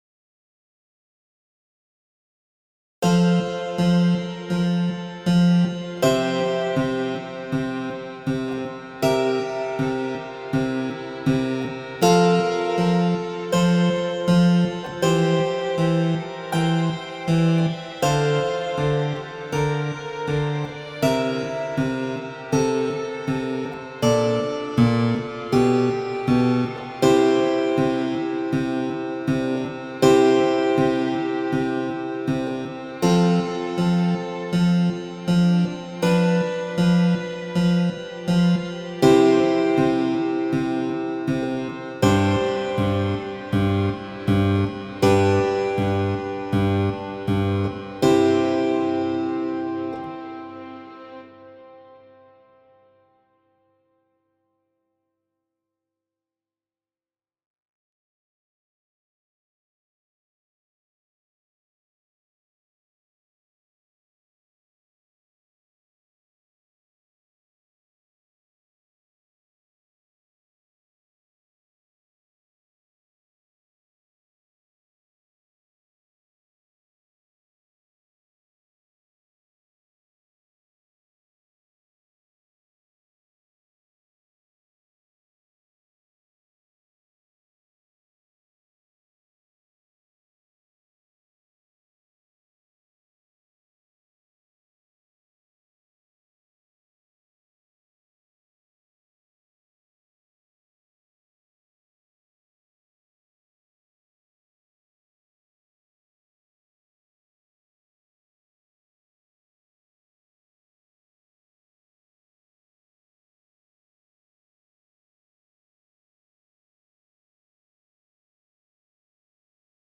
Partitura do segundo movemento (Largo, fragmento) do concerto "O inverno" ("As catro estacións", A. Vivaldi)
Actividade 4: Tocar a melodía da voz 1, xunto co acompañamento do clavicémbalo e co violín, que soa máis piano, a tempo.